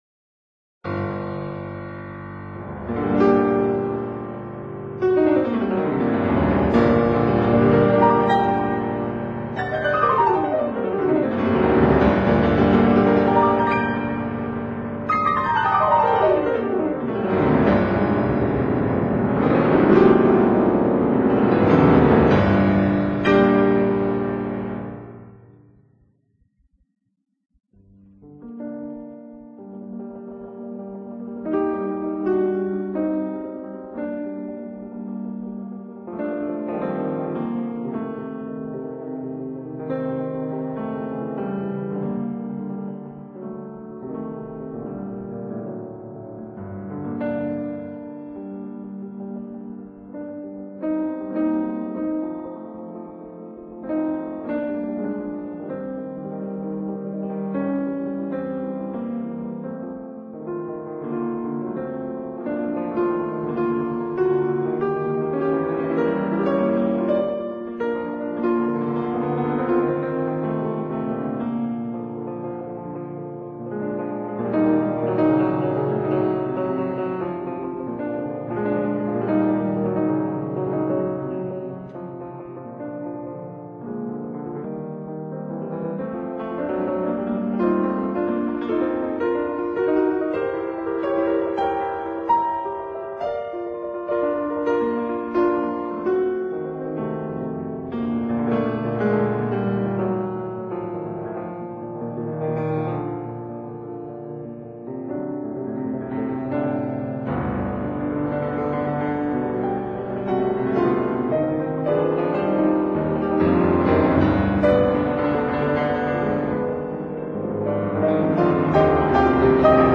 d'après Paganini 6, for piano, S.140
아르페지오를 반복한다.
트레몰로의 연습곡.